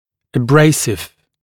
[ə’breɪsɪv][э’брэйсив]абразивный, шлифующий